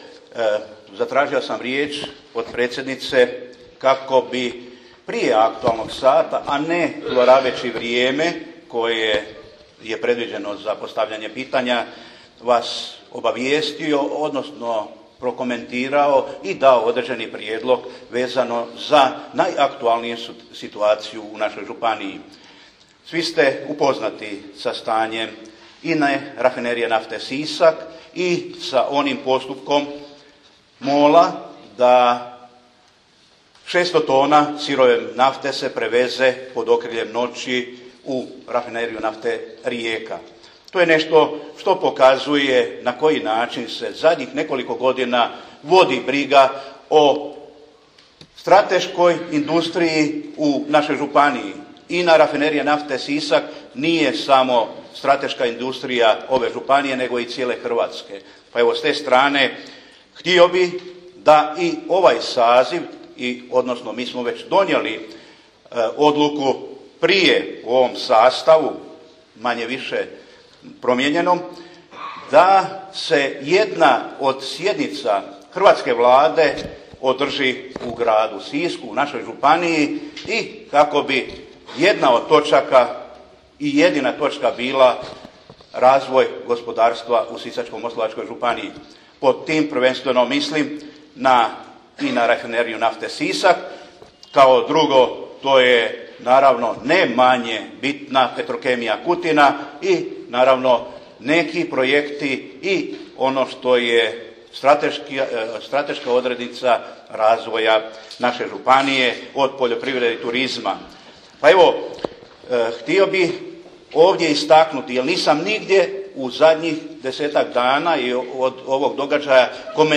Izjava župana Žinića: